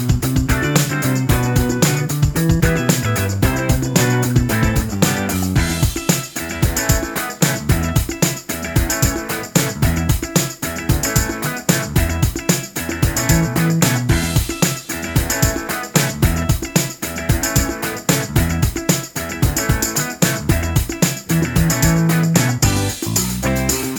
for solo male Disco 3:14 Buy £1.50